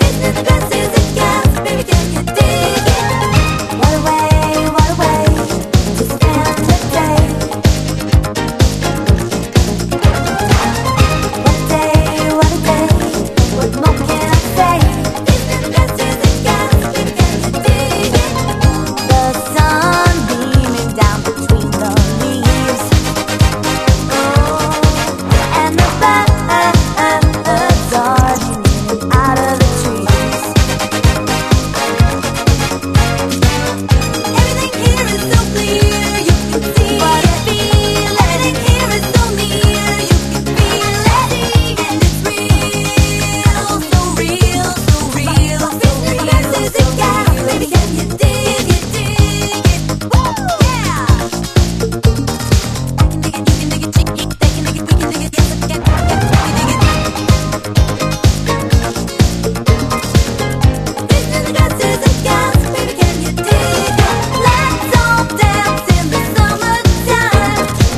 NEW WAVE / DANCE CLASSIC / DISCO
エレクトリック・ファンク・ポップ/ファンカラティーナ！
ゴージャスでダンサブルなエレクトリック・ホワイト・ファンクな名盤！